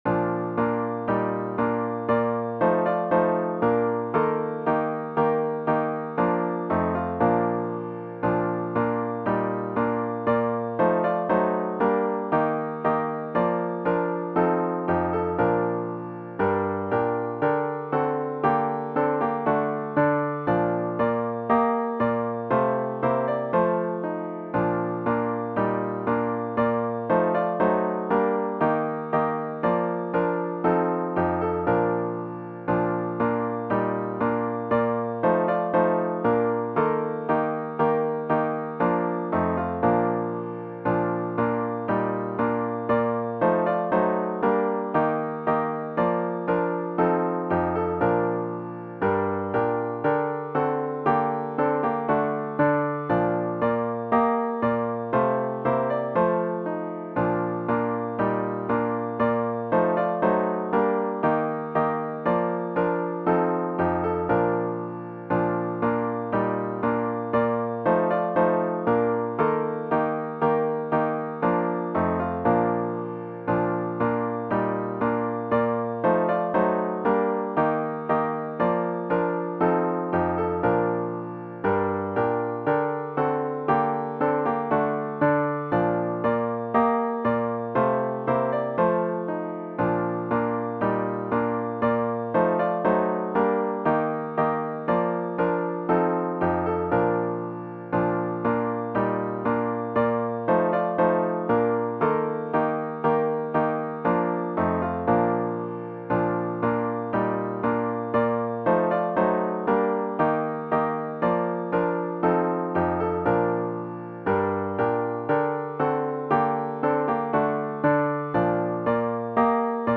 OPENING HYMN   “Love Divine, All Loves Excelling”   GtG 366   [Tune 761]